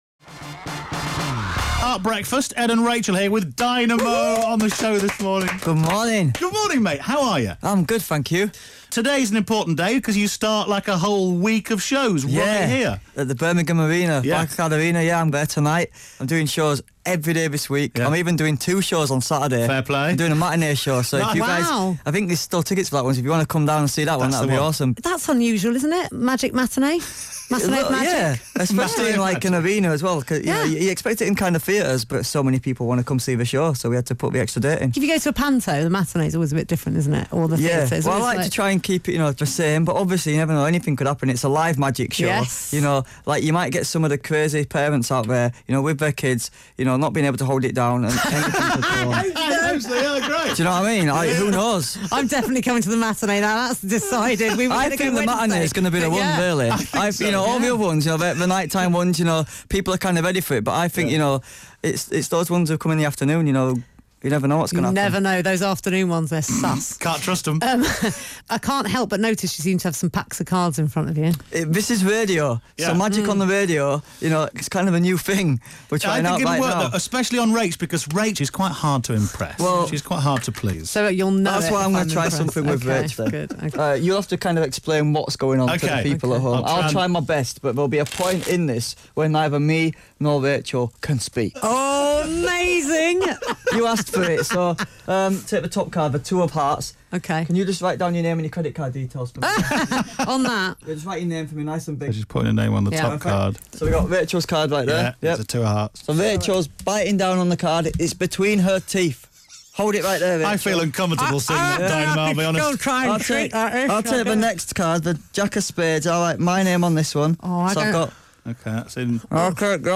Dynamo - Interview